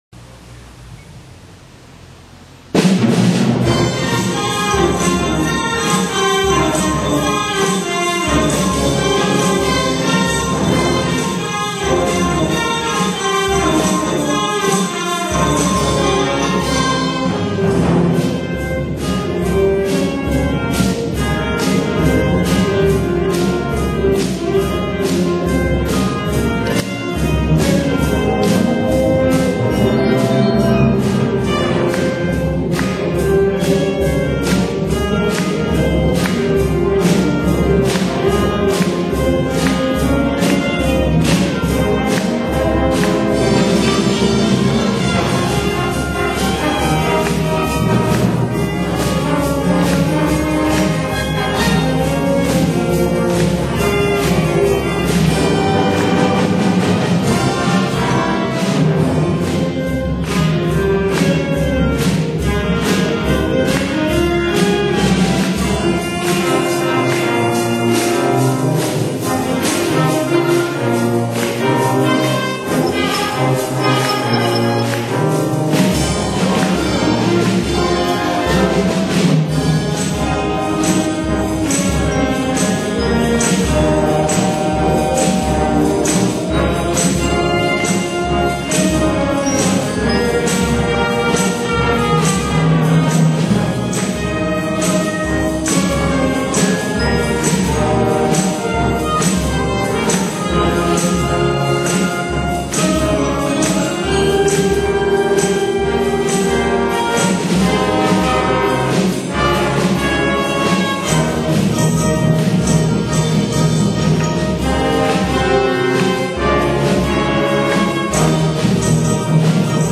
１２月１６日（土）吹奏楽部クリスマスコンサート 実施
１２月１６日（土） 午後３時からクリスマスコンサートを行いました。 昨年までは平日の放課後、校門付近で行っていましたが、今年は体育館で実施しました。